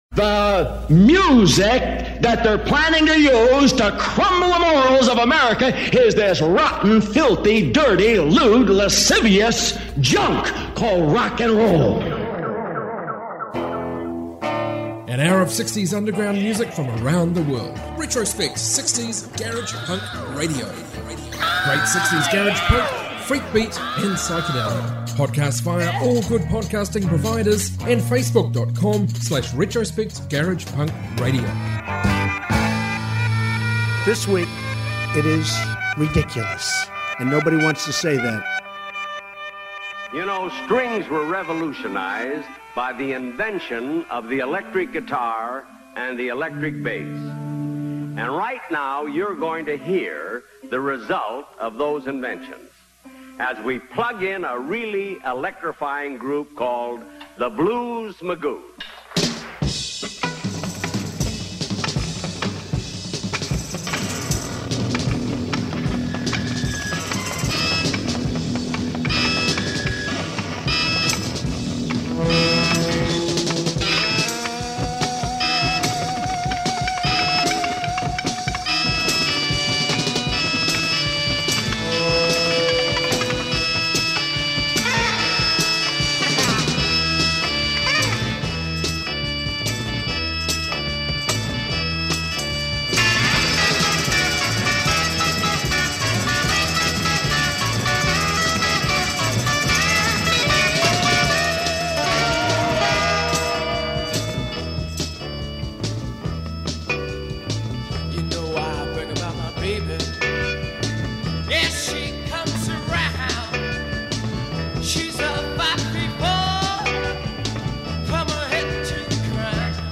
60s global garage